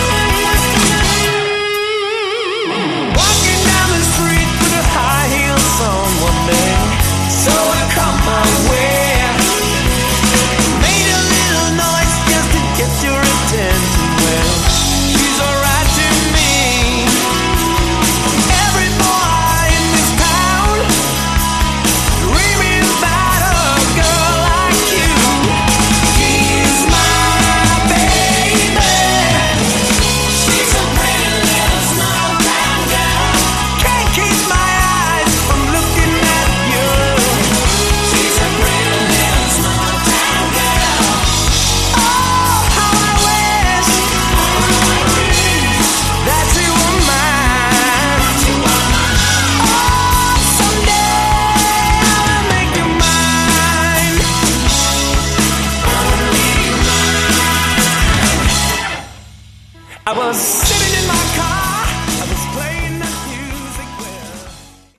Category: Melodic Hard Rock
Vocals
Guitars
Bass
Drums
Keyboards